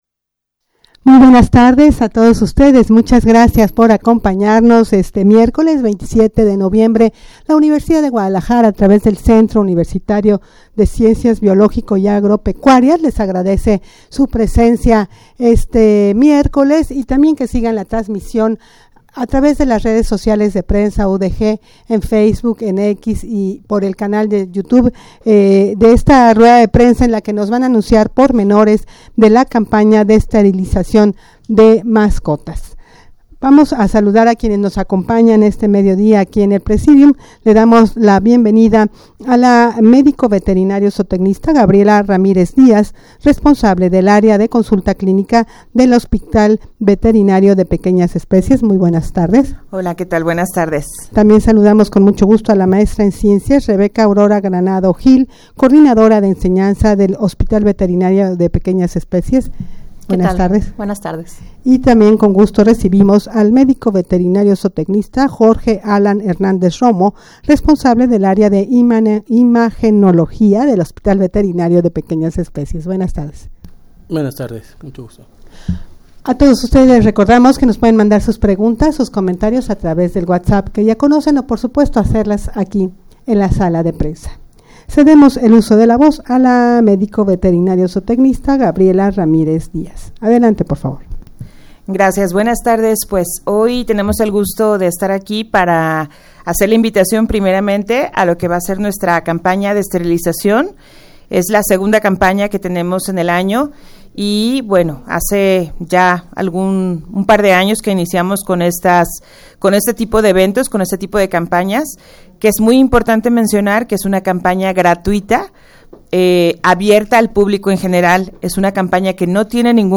Audio de la Rueda de Prensa
rueda-de-prensa-para-anunciar-pormenores-de-la-campana-de-esterilizacion-de-mascotas.mp3